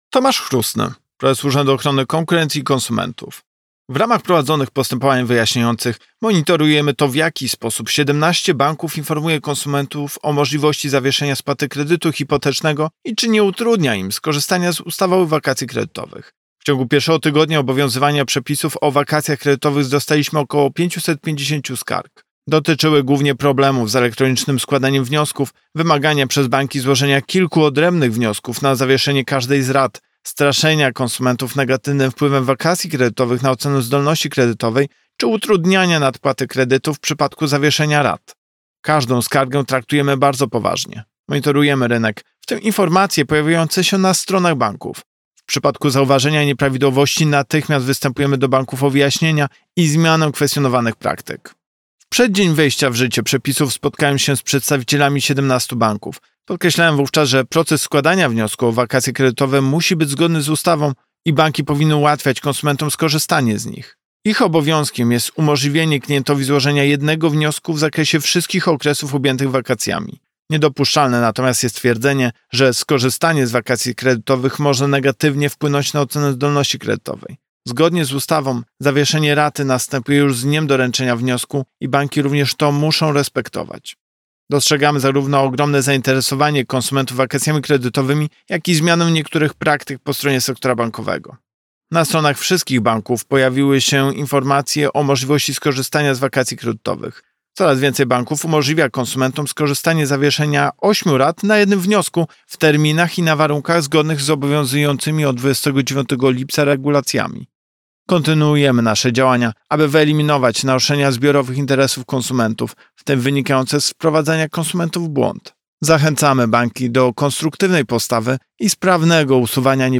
Wypowiedź Prezesa UOKiK Tomasza Chróstnego z 5 sierpnia 2022 r..mp3